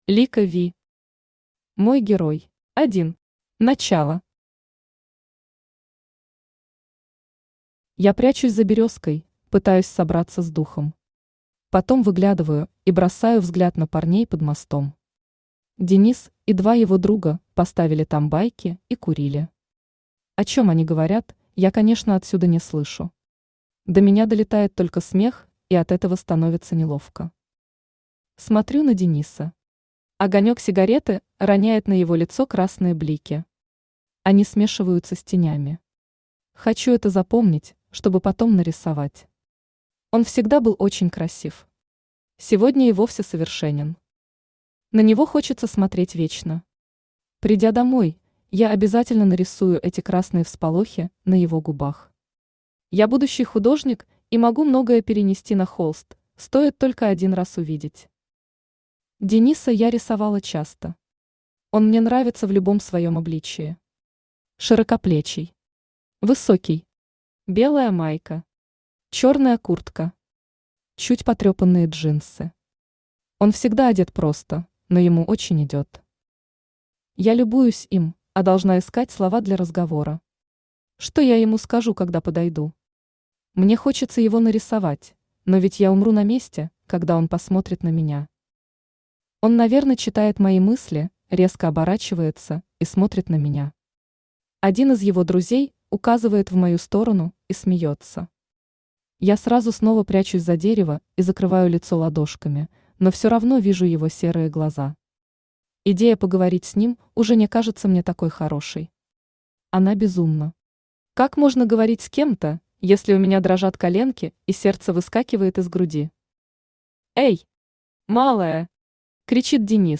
Aудиокнига Мой герой Автор Лика Ви Читает аудиокнигу Авточтец ЛитРес.